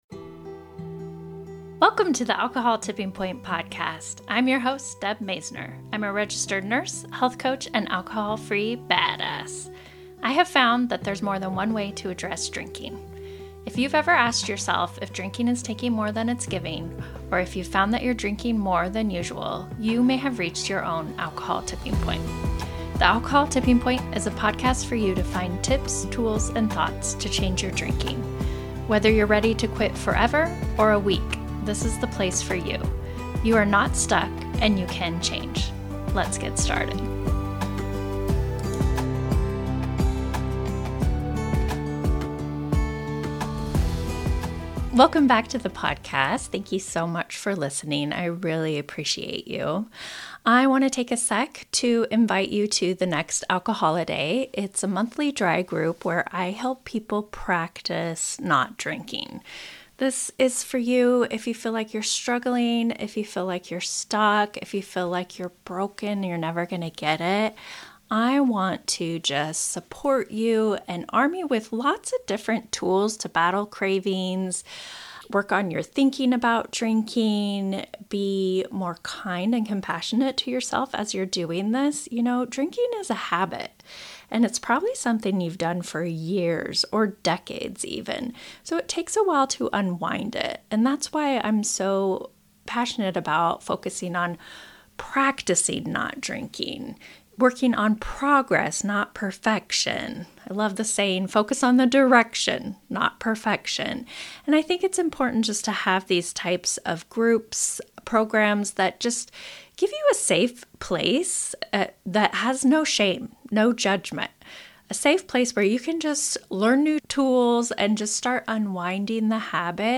We had such a heartfelt conversation that I wanted to share it on my show as well.